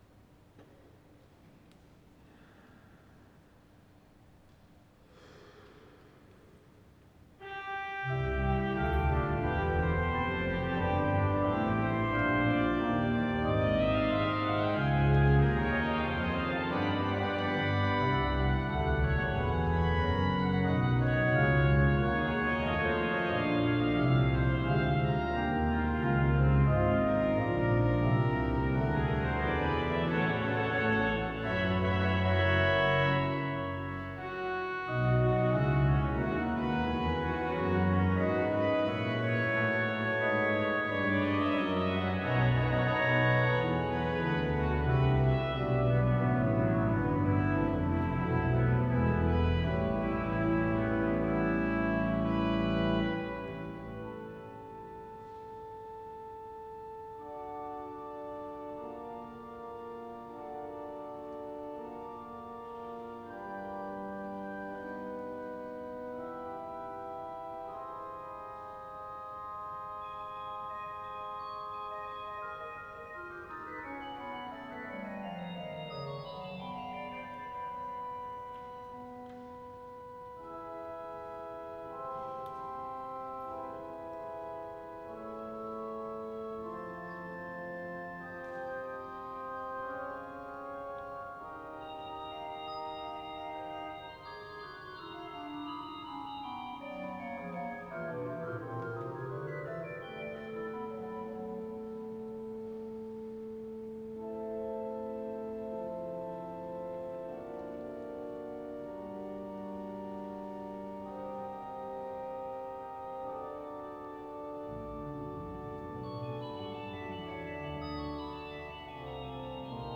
Organ Recital